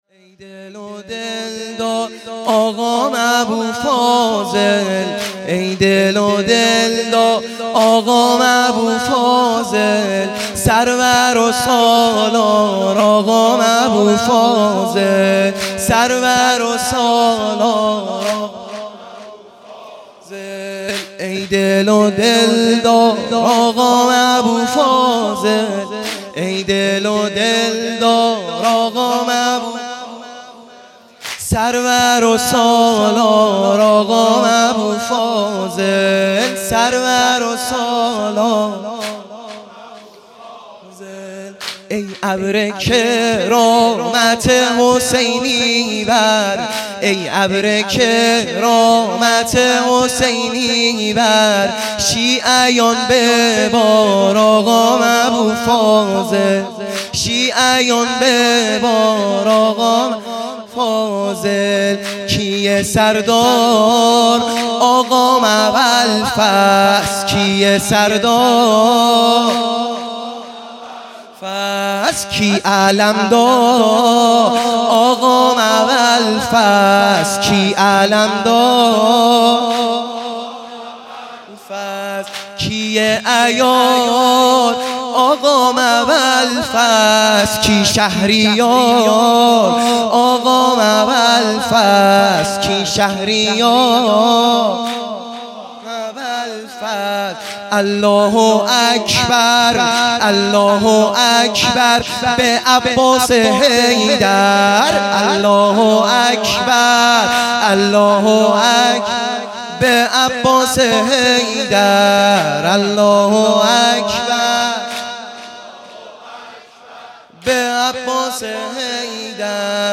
واحد | ای دل و دلدار
شب نهم محرم الحرام ۱۳۹۶